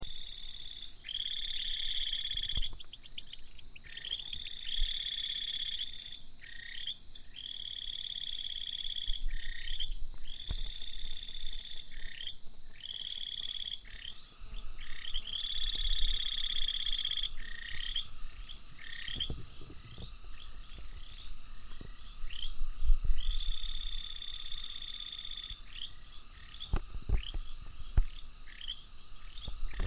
日本樹蛙 Buergeria japonica
錄音地點 雲林縣 斗六市 檨仔坑
錄音環境 水溝
10隻以上競叫